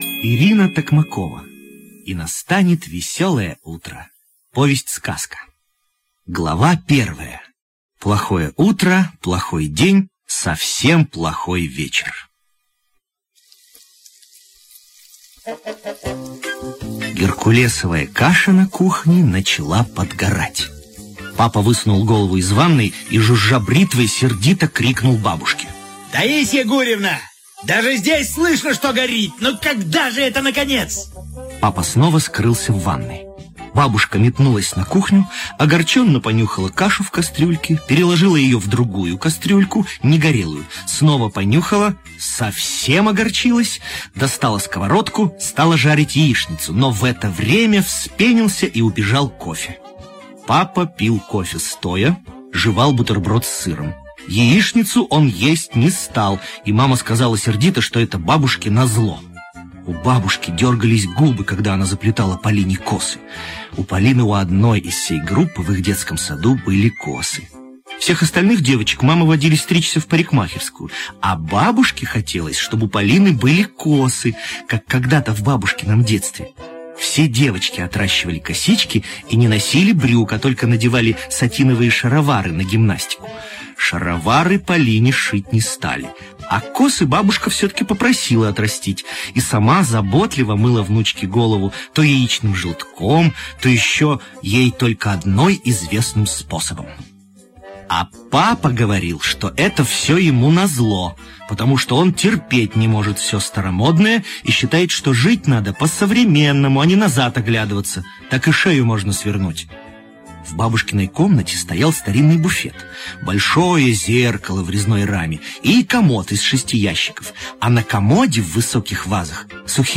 И настанет веселое утро - аудиосказка Ирины Токмаковой - слушать онлайн